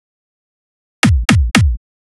116 BPM Beat Loops Download